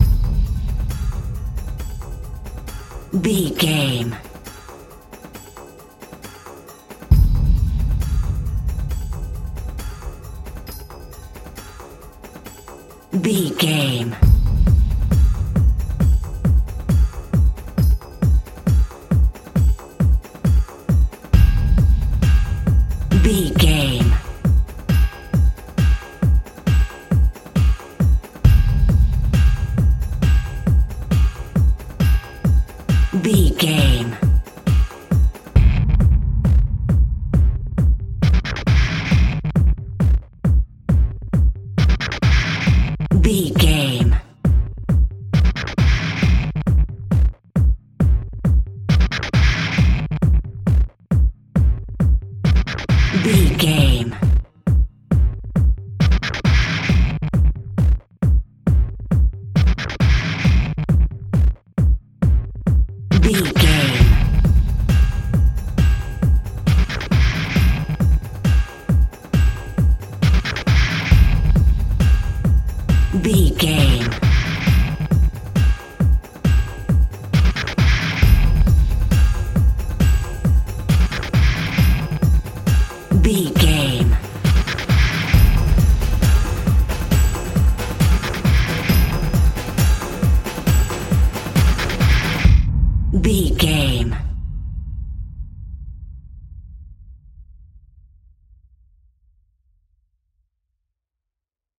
Aeolian/Minor
Fast
tension
ominous
dark
eerie
driving
synthesiser
drums
drum machine